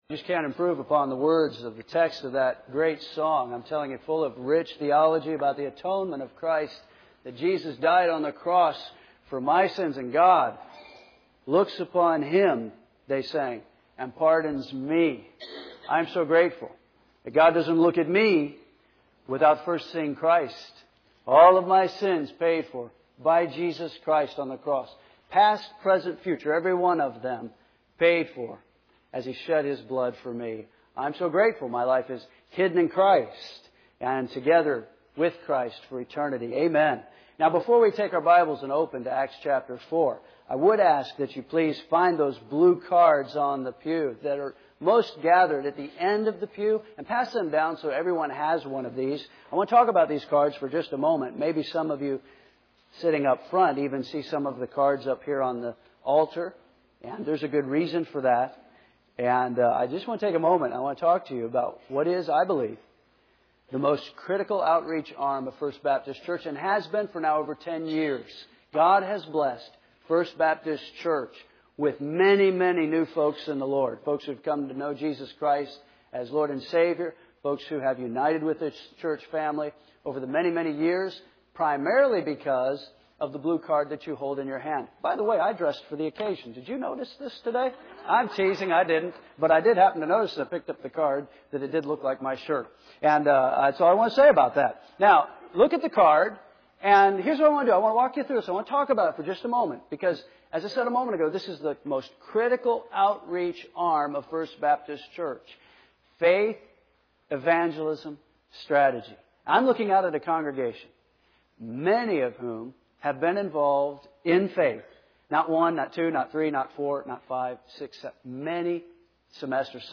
First Baptist Church Henderson, KY